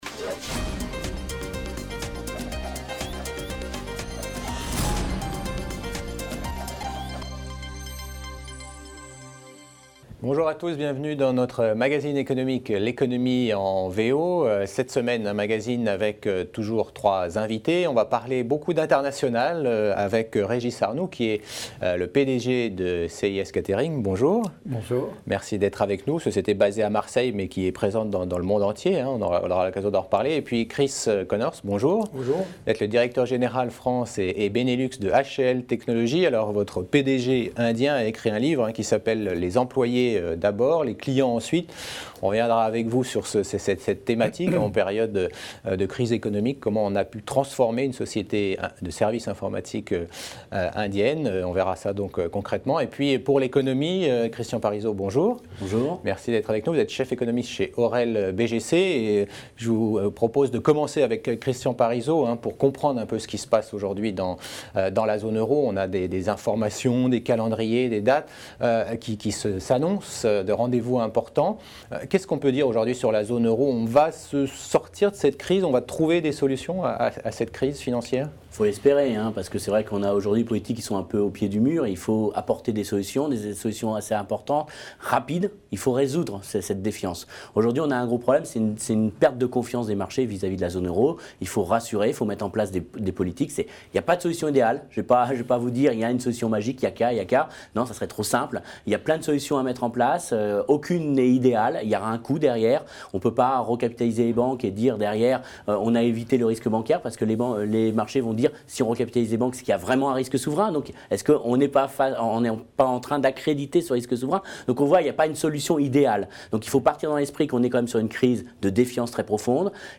Fin de la première partie du Débat économique « l’Economie en VO »